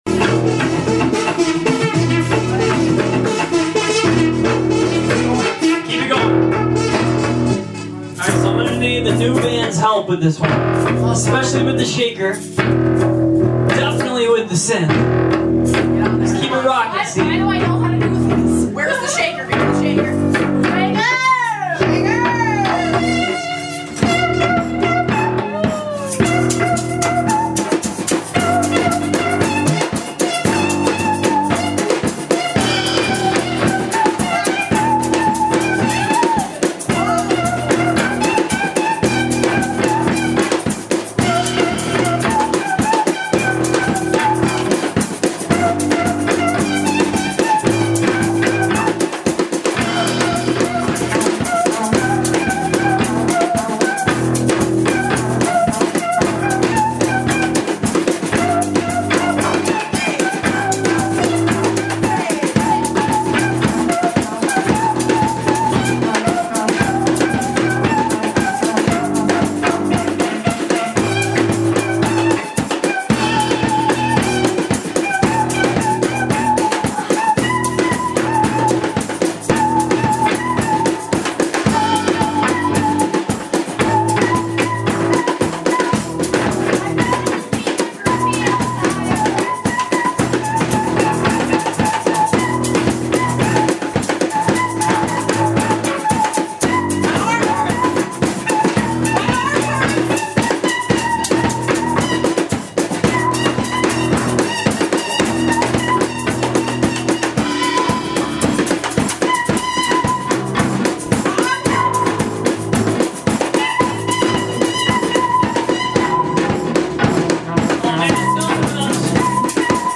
xmasmegajam.mp3